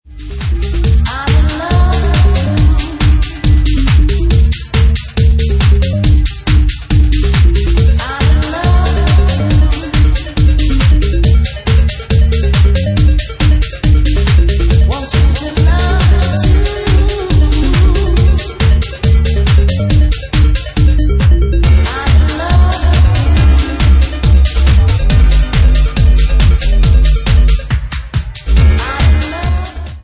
Genre:Bassline House
139 bpm